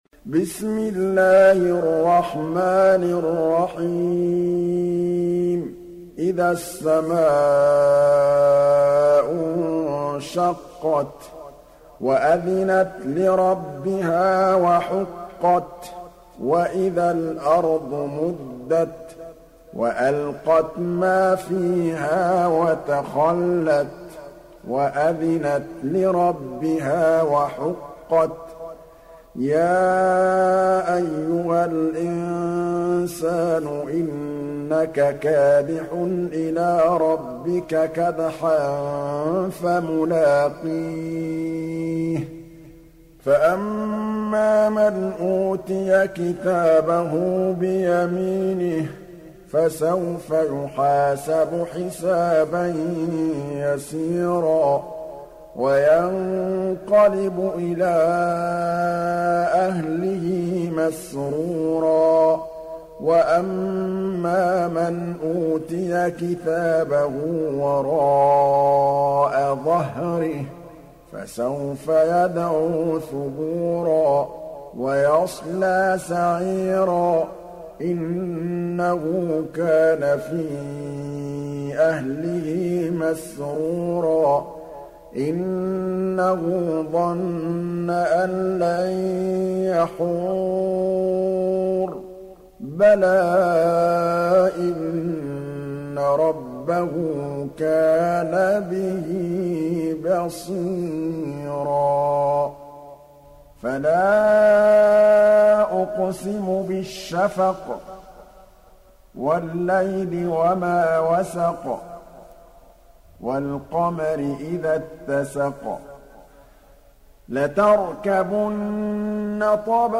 دانلود سوره الانشقاق mp3 محمد محمود الطبلاوي (روایت حفص)